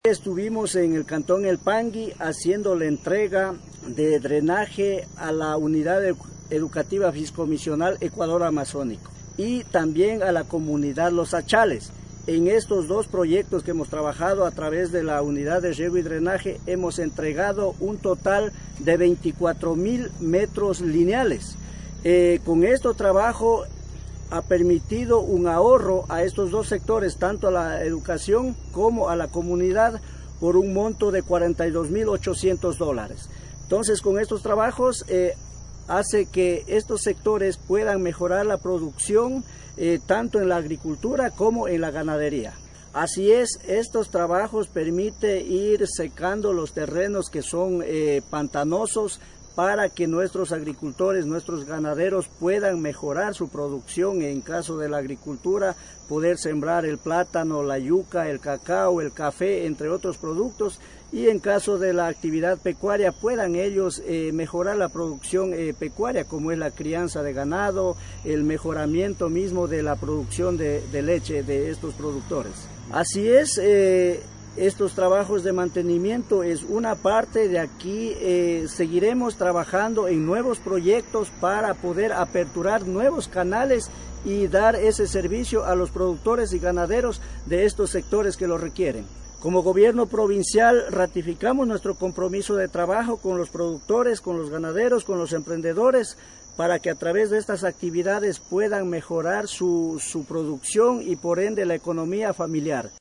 VÍCTOR SARANGO, VICEPREFECTO